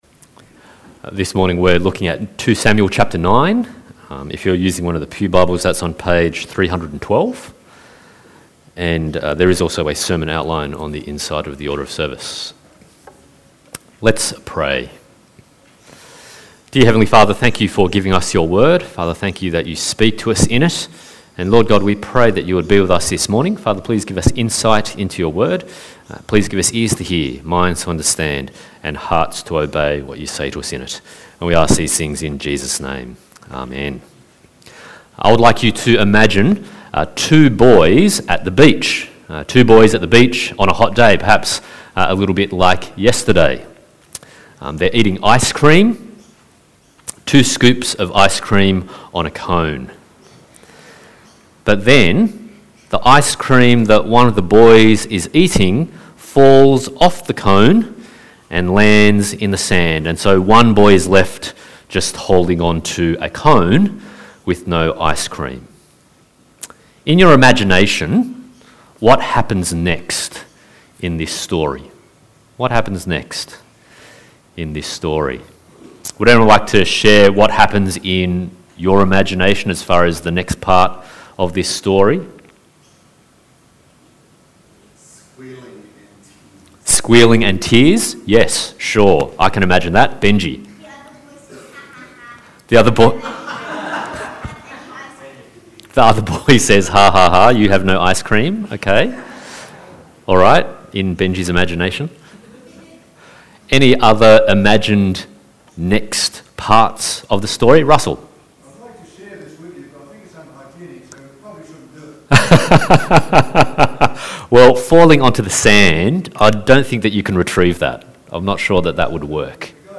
2 Samuel 1-10 Passage: 2 Samuel 9:1-13, 1 Samuel 20:4-17, Ephesians 2:1-7 Service Type: Sunday Morning